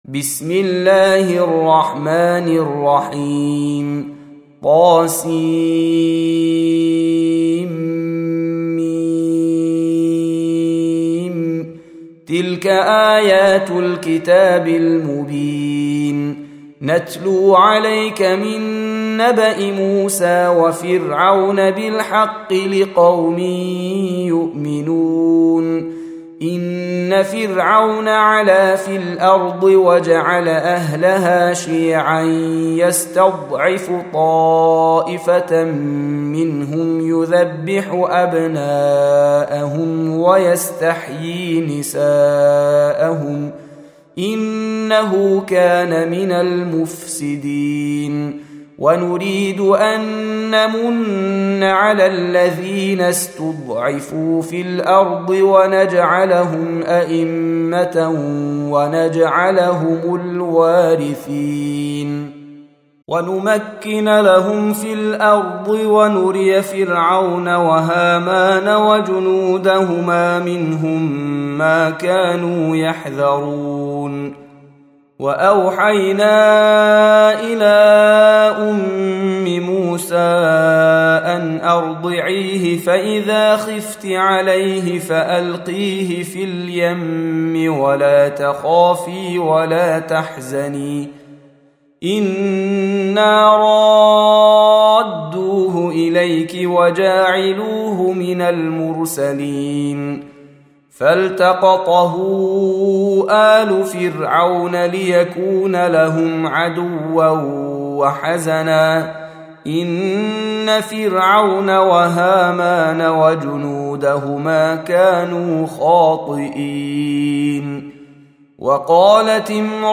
Surah Sequence تتابع السورة Download Surah حمّل السورة Reciting Murattalah Audio for 28. Surah Al-Qasas سورة القصص N.B *Surah Includes Al-Basmalah Reciters Sequents تتابع التلاوات Reciters Repeats تكرار التلاوات